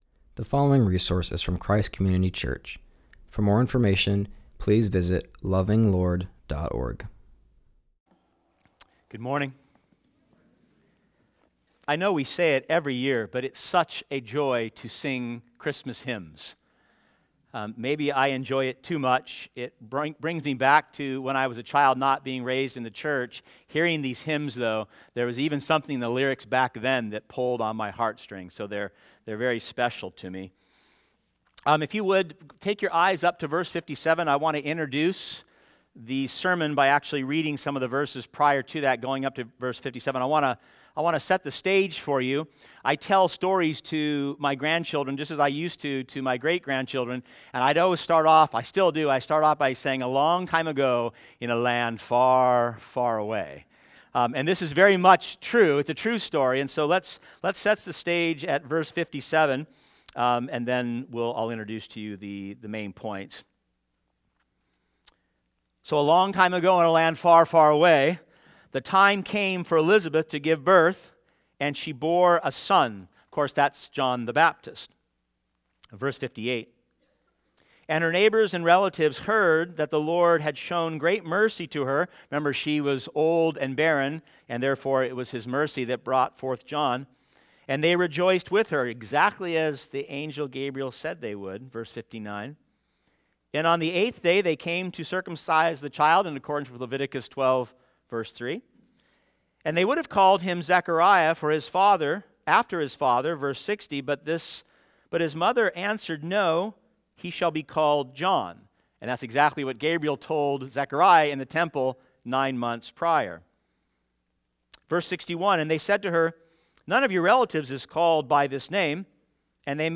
preaches from Luke 1:57-80.